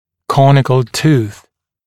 [‘kɔnɪkl tuːθ][‘коникл ту:с]зуб конической формы
conical-tooth.mp3